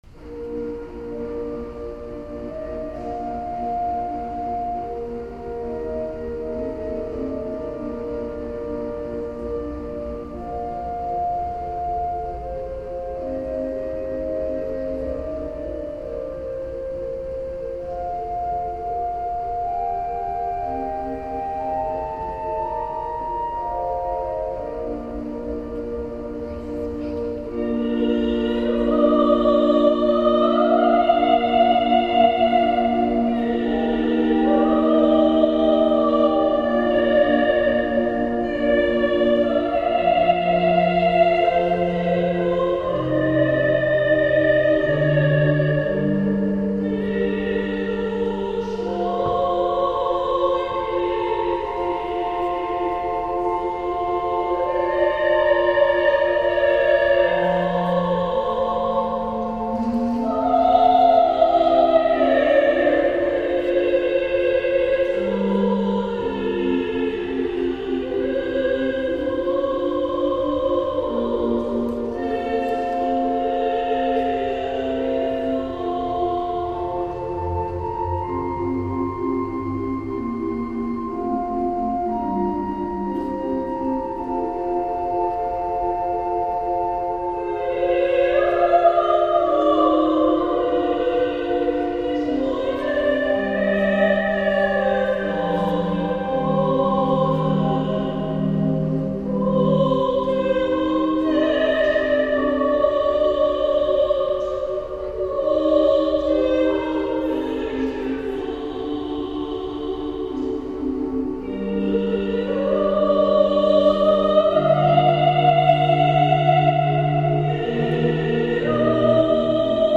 Posłuchaj mnie - utwory wykonane z towarzyszeniem organów